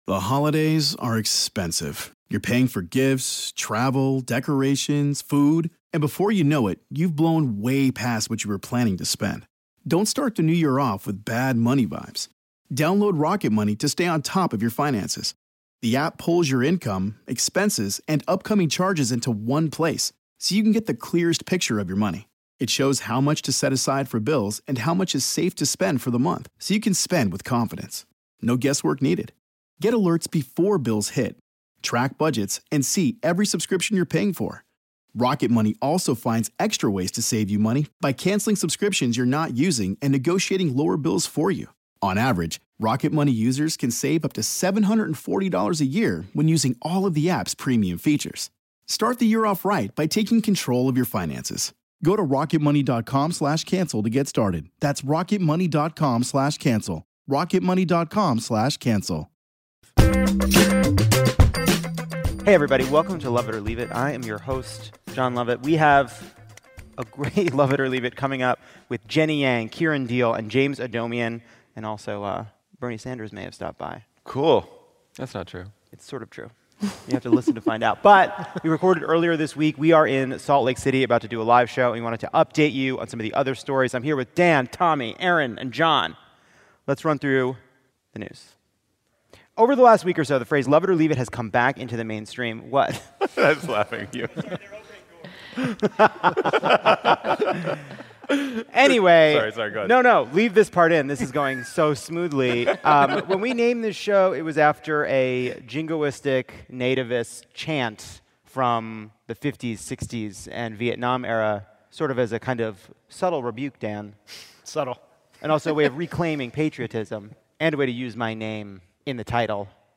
Backstage in Salt Lake City, Lovett catches up with Jon Favreau, Tommy Vietor, Dan Pfeiffer, and Erin Ryan on Trump's fascist rally, the CNN debate draw, and the Cats/Top Gun divide.